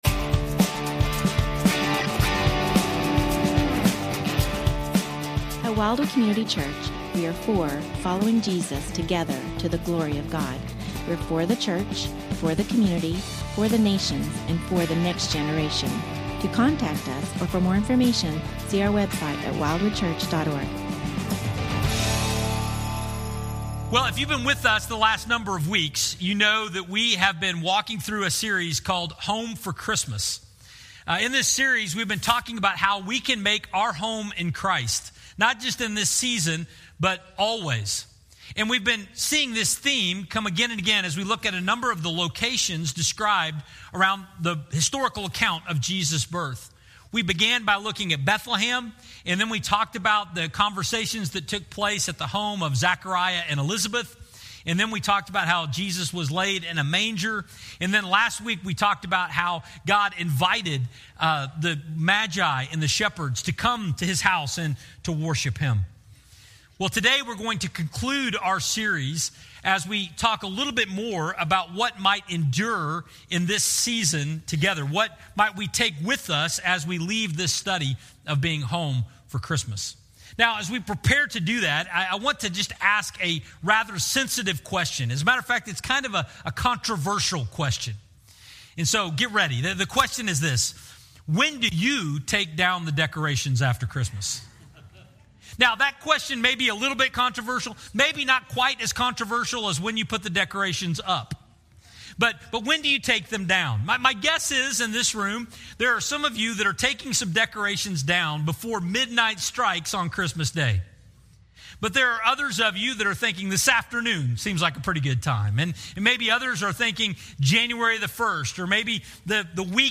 Whys of Worship (part 1) Sermon Audio, Video, & Questions